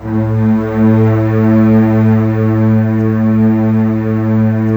Index of /90_sSampleCDs/AKAI S-Series CD-ROM Sound Library VOL-7/ORCH STRINGS